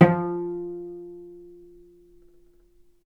vc_pz-F3-ff.AIF